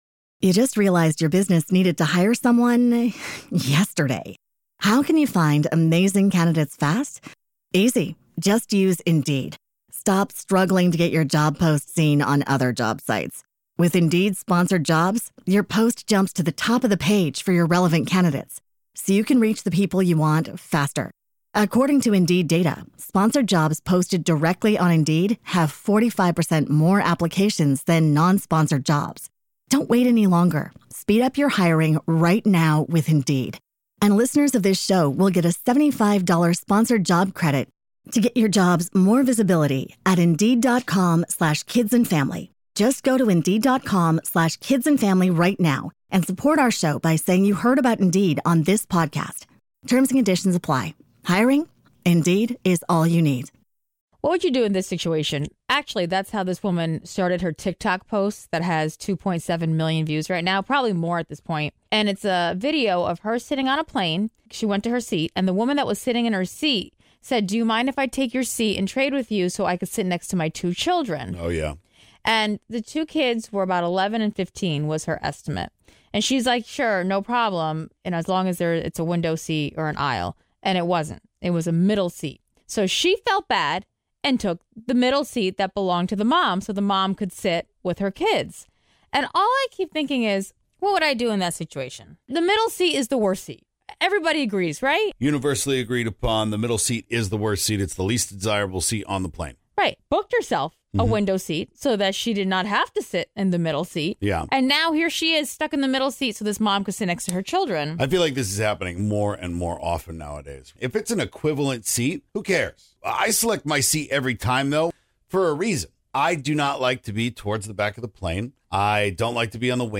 Moms (and Dads) share all the crazy things that their kids have put them through this week that have definitely earned them a margarita!